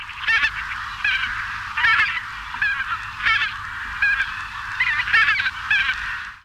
Oie rieuse
Anser albifrons
oie-rieuse.mp3